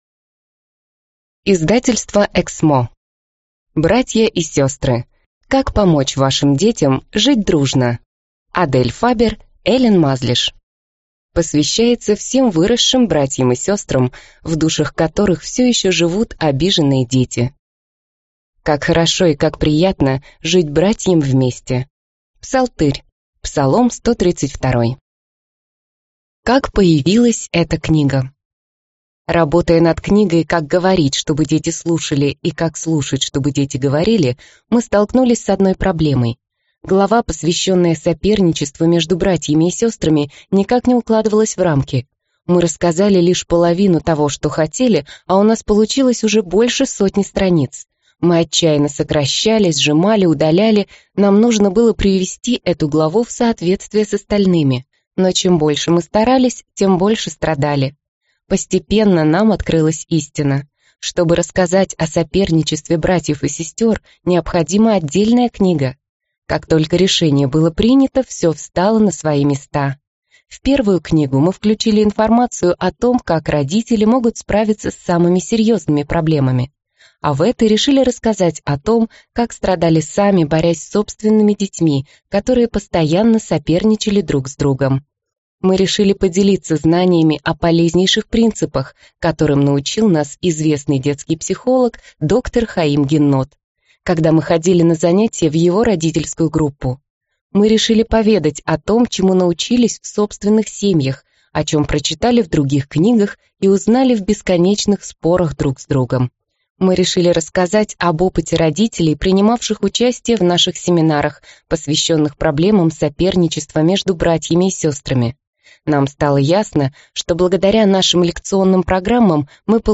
Аудиокнига Братья и сестры. Как помочь вашим детям жить дружно | Библиотека аудиокниг